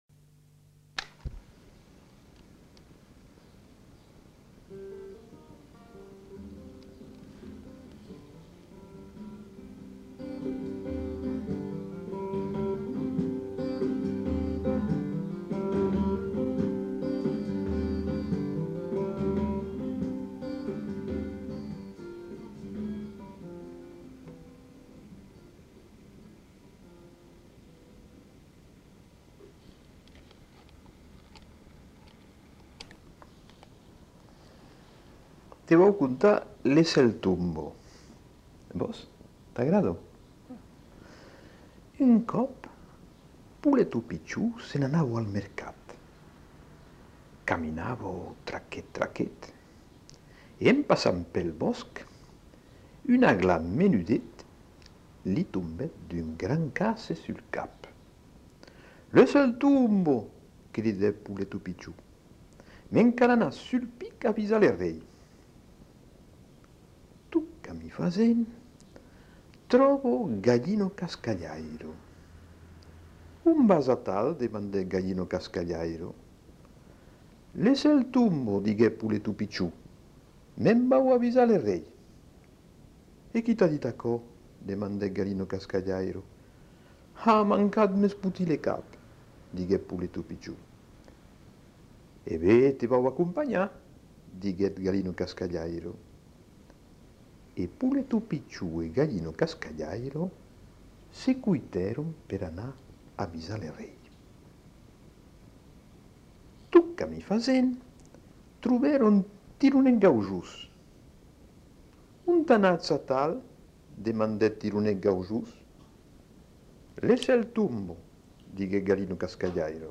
Genre : conte-légende-récit
Type de voix : voix d'homme
Production du son : lu
Notes consultables : Extrait musical en introduction.